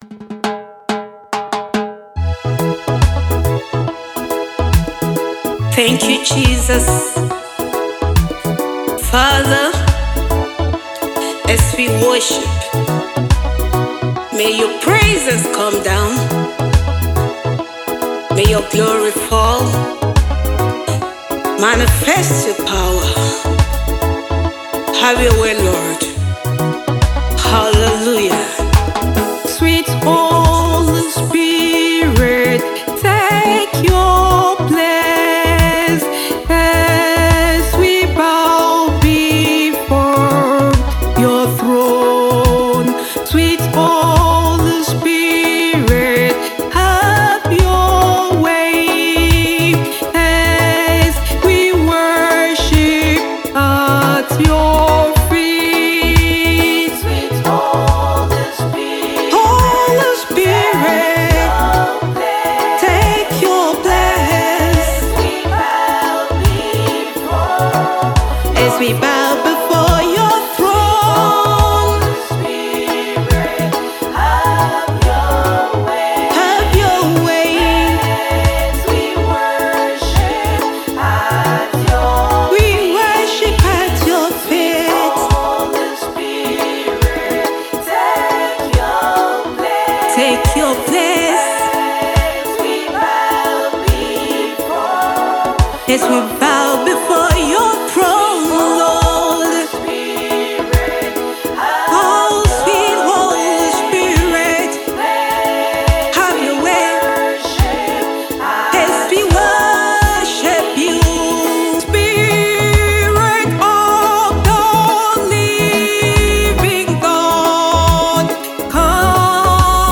Gospel Music Minister Evangelist
deep worship song